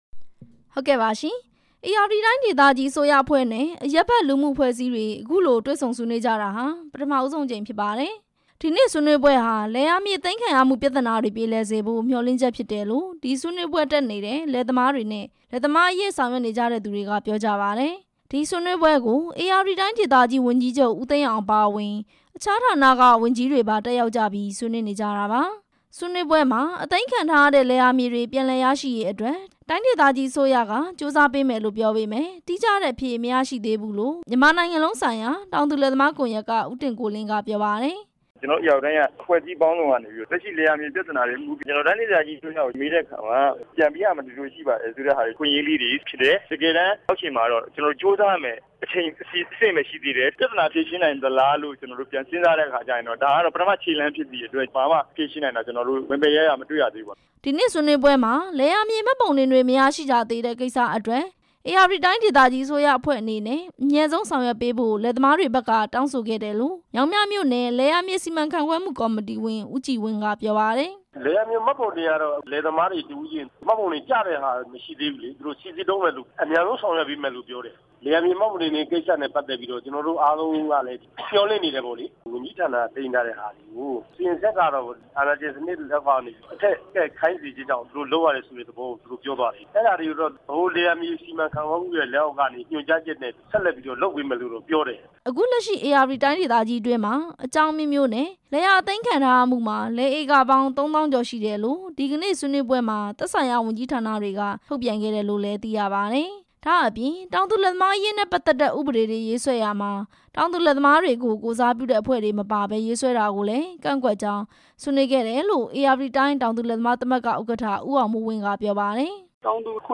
ဆွေးနွေးပွဲအကြောင်း တင်ပြချက်